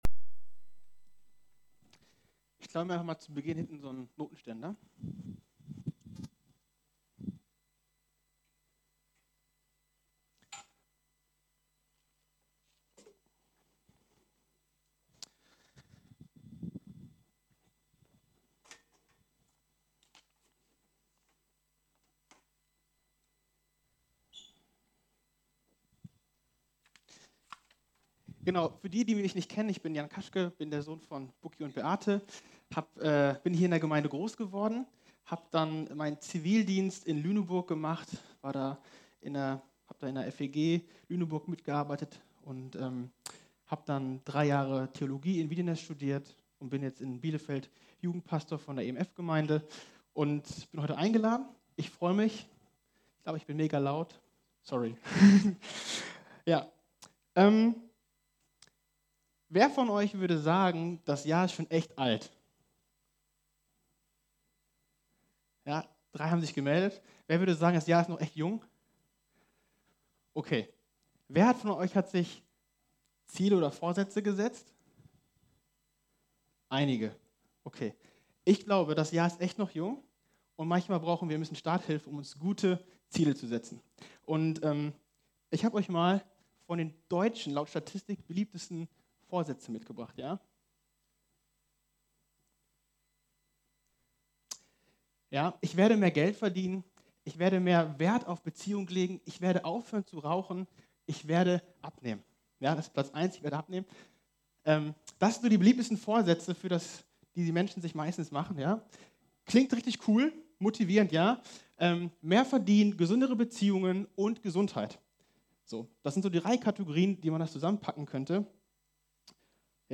Predigt vom 19. Januar 2020 – efg Lage